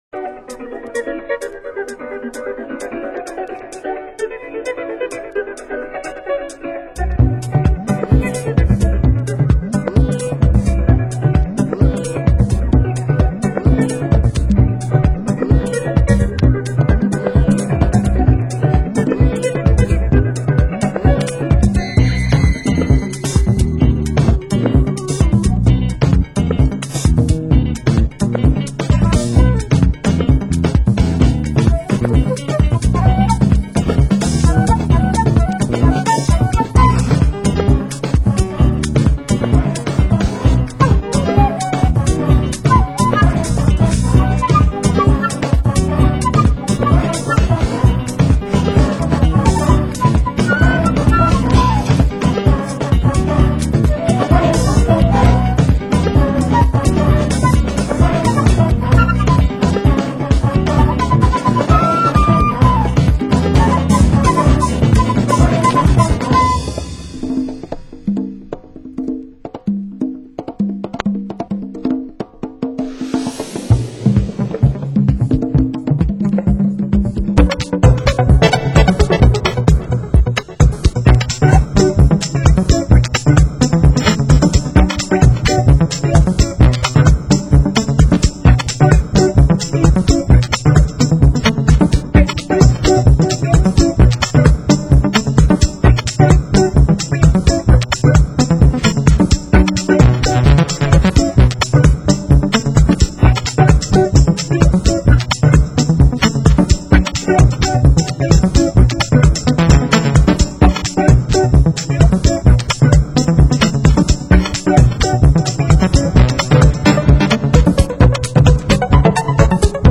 Genre: Deep House